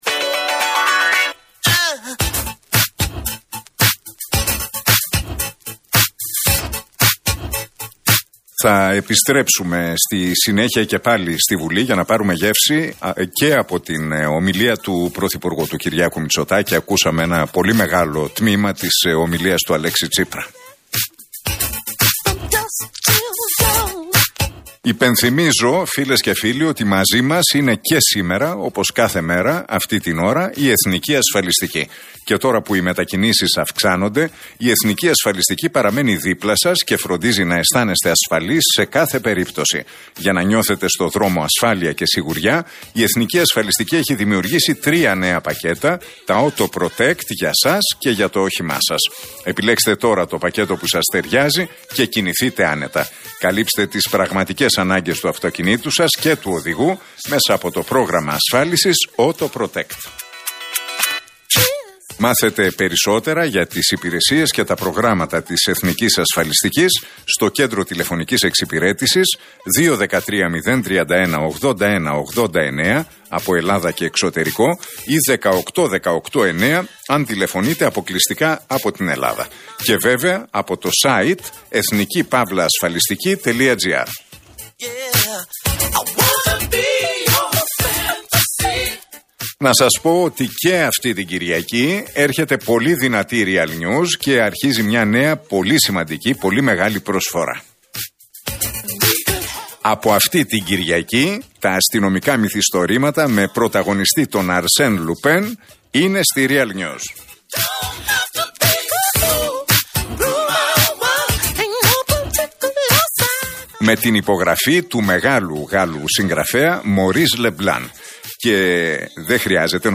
Ακούστε την εκπομπή του Νίκου Χατζηνικολάου στον Real Fm 97,8, τη Δευτέρα 18 Οκτωβρίου 2021.